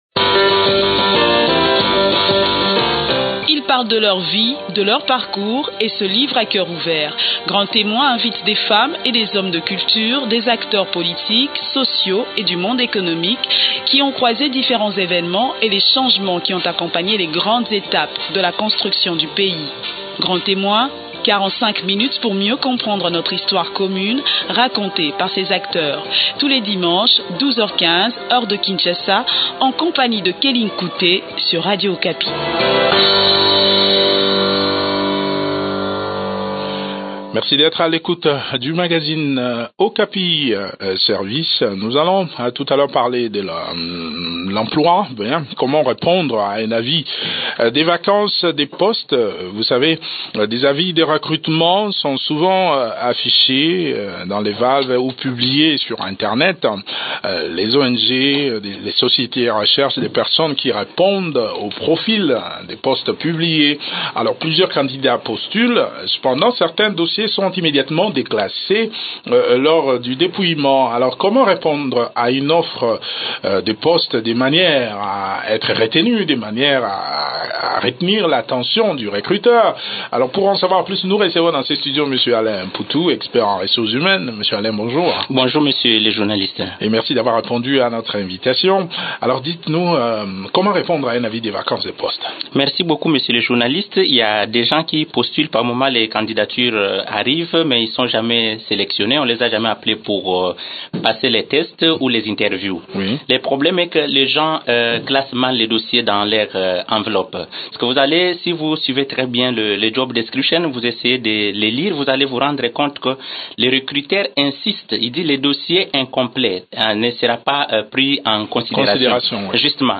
expert en ressources humaines.